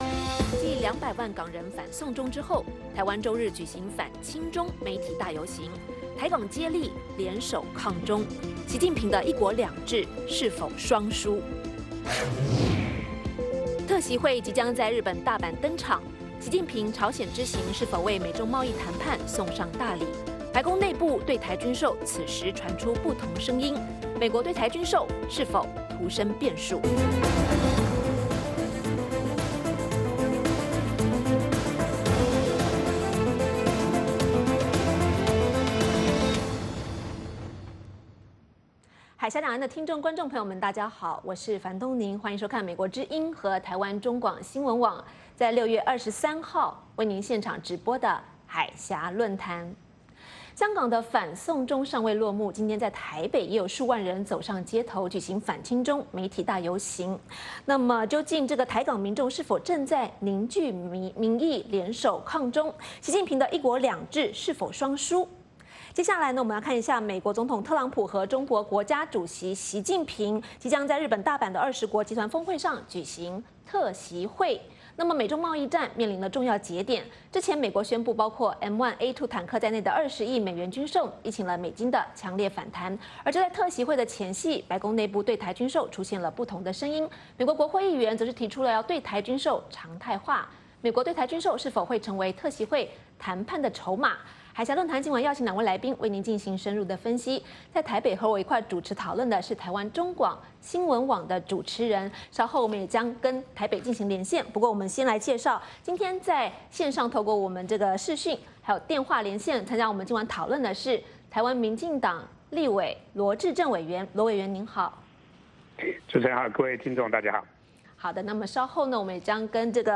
美国之音中文广播于北京时间每周一晚上8－9点重播《焦点对话》节目。《焦点对话》节目追踪国际大事、聚焦时事热点。邀请多位嘉宾对新闻事件进行分析、解读和评论。或针锋相对、或侃侃而谈。